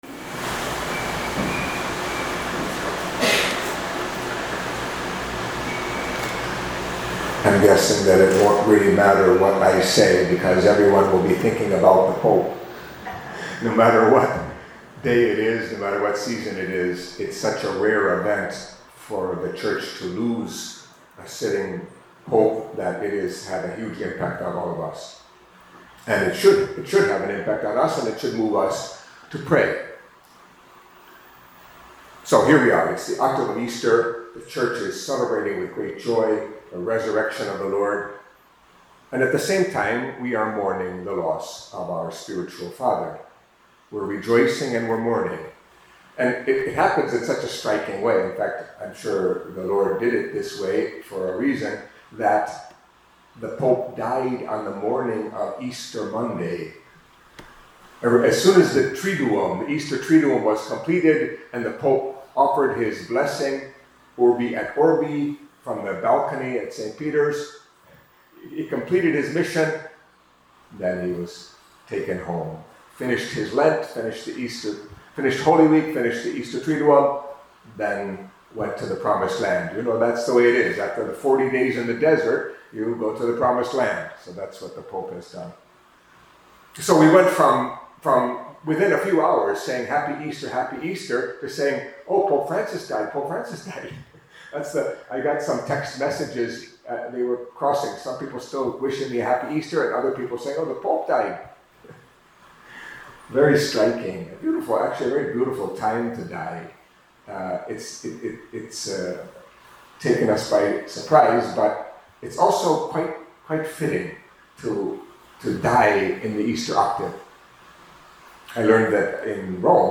Catholic Mass homily for Tuesday in the Octave of Easter